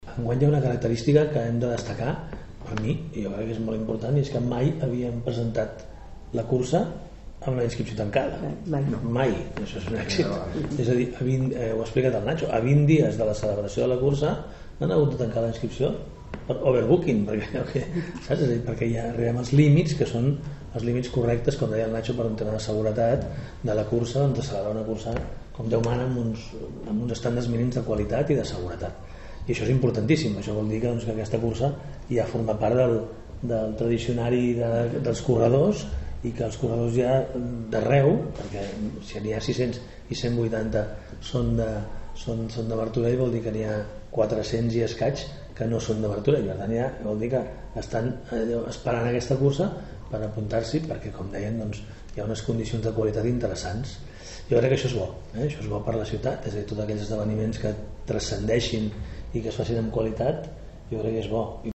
Presentada aquesta tarda a la Casa de la Vila la 26a edició de la Cursa de Martorell, una cita coorganitzada per l’Ajuntament i el Martorell Atlètic Club (MAC).
Xavier Fonollosa, alcalde de Martorell